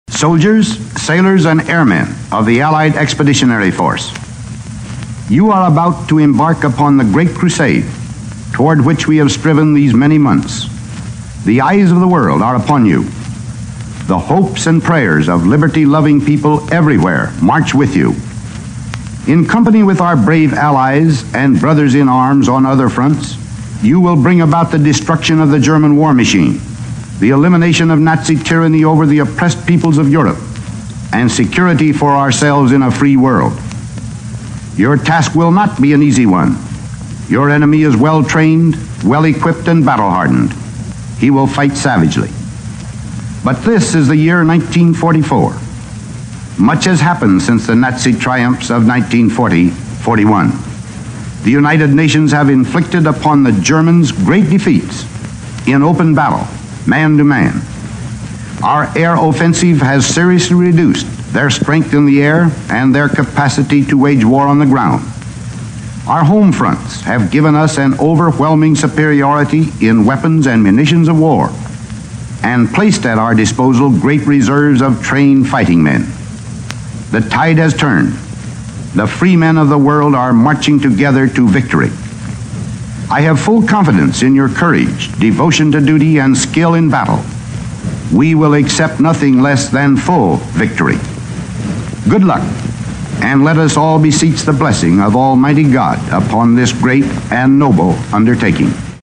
Recorded May 28, 1944 for broadcast June 5